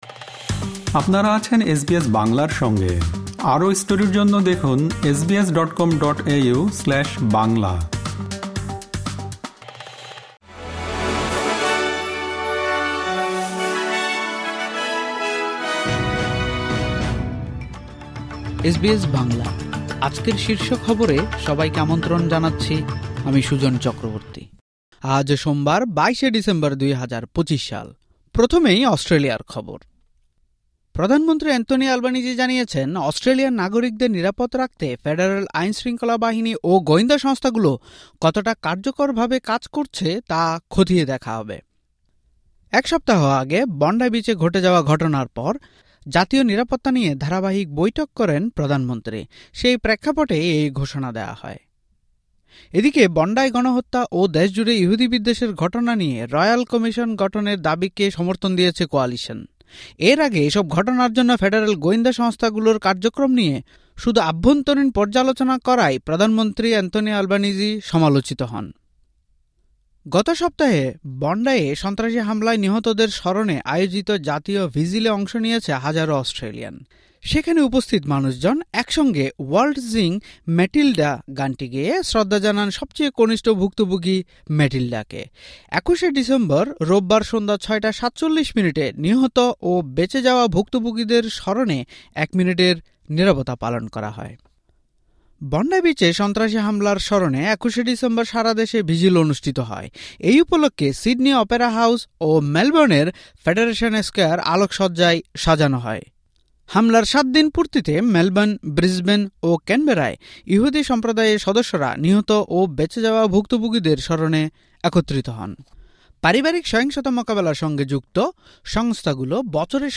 এসবিএস বাংলা শীর্ষ খবর: ২২ ডিসেম্বর, ২০২৫